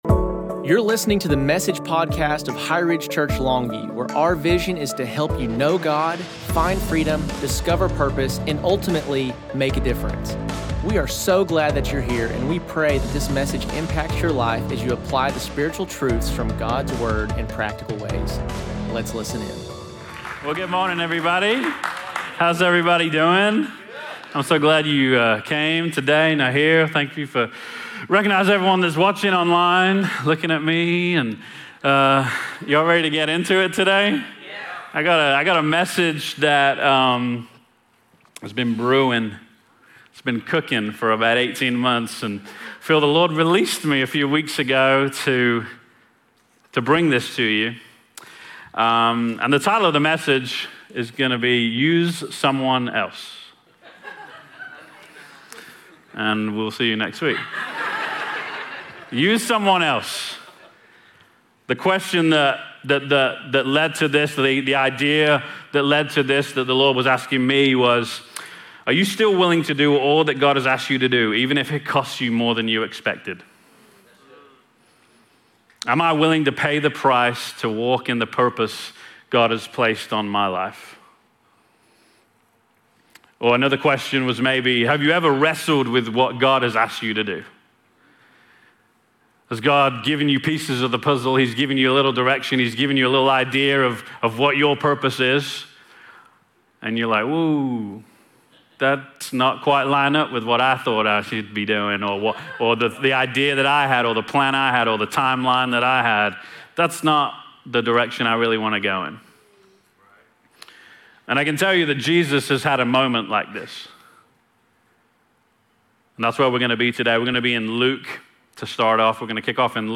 Sunday, July 20, 2025 Message: